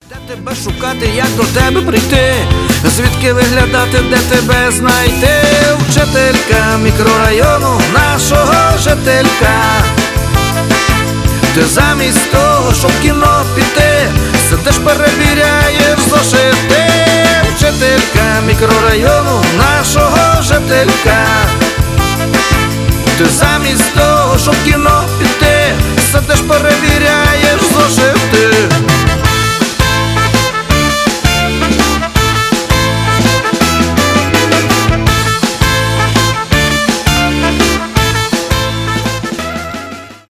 ска , рок